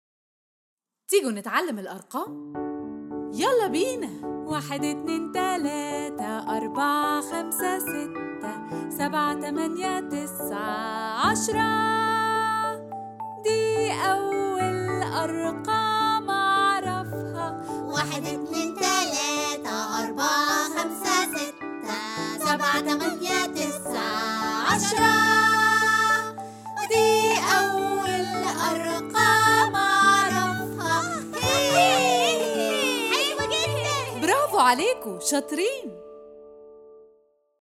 Their catchy rhythm and use of repetition gently boost their memory and vocabulary.